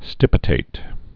(stĭpĭ-tāt)